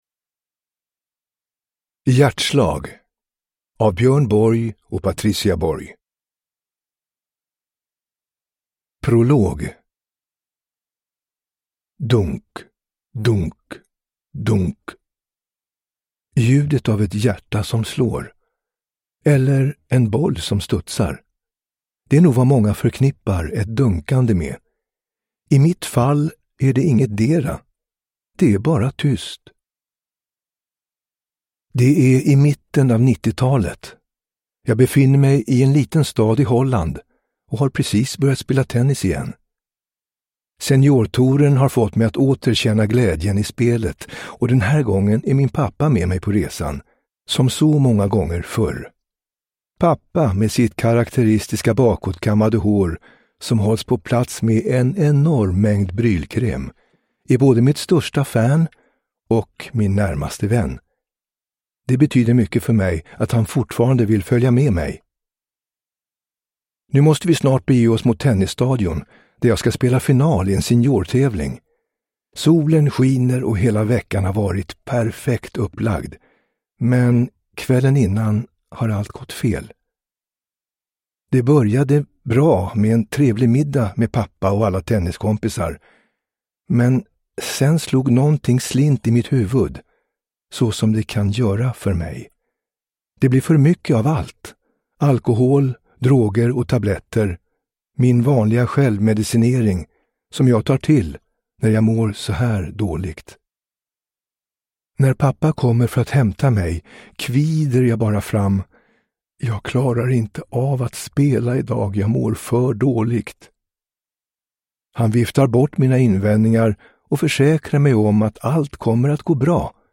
Hjärtslag : en självbiografi (ljudbok